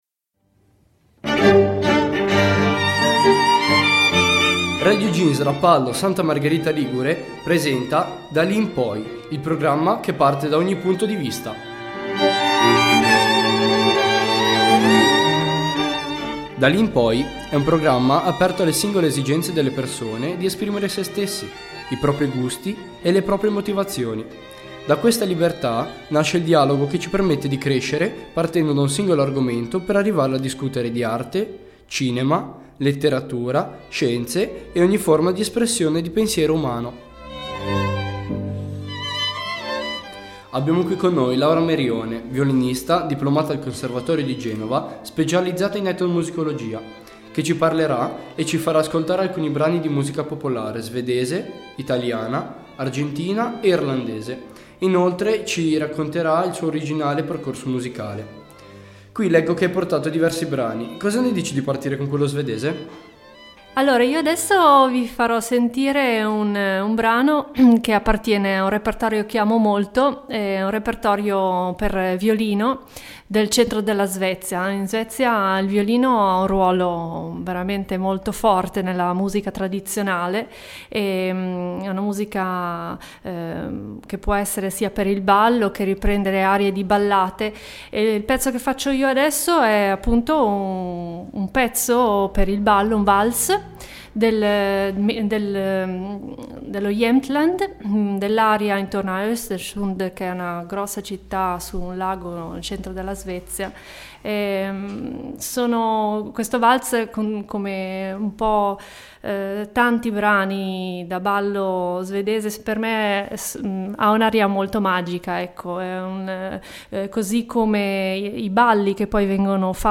Programma contenitore di nuova ideazione. Prima puntata dedicata alla musica tradizionale con ospite la violinista ed etnomusicologa